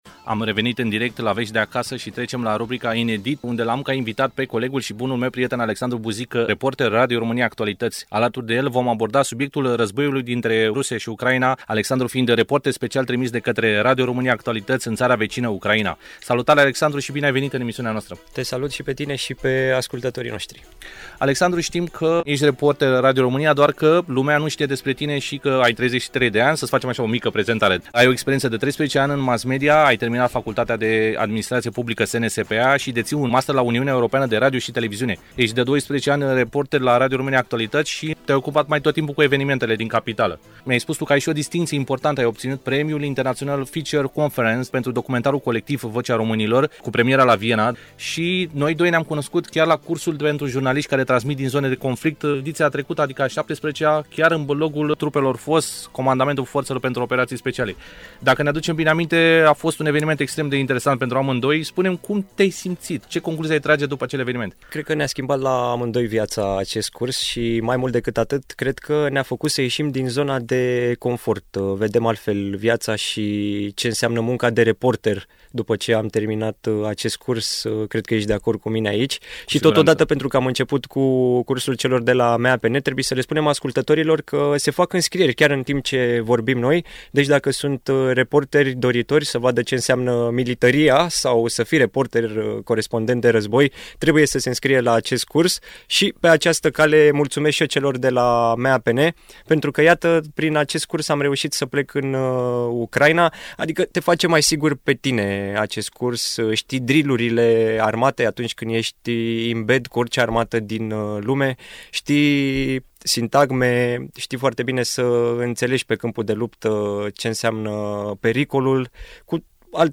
interviul